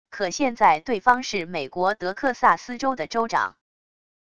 可现在对方是美国德克萨斯州的州长wav音频生成系统WAV Audio Player